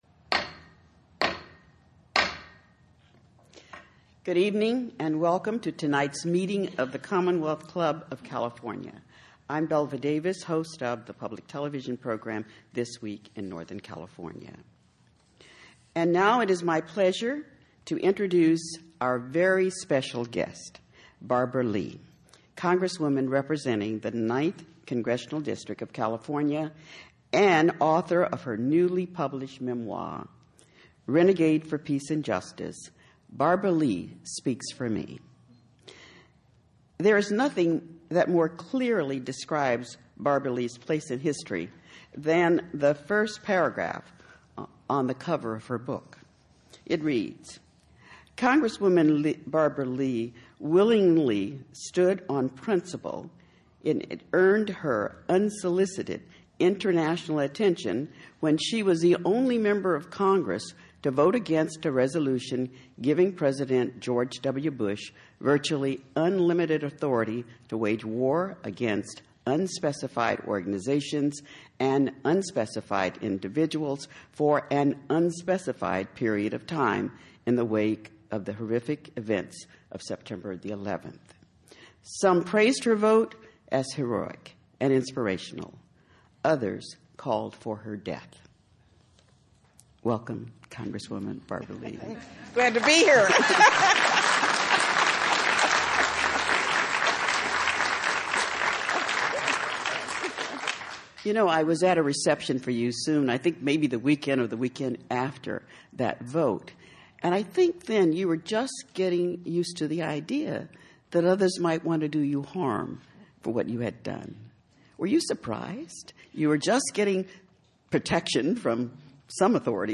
Barbara Lee: In-Conversation with the Renegade for Peace and Justice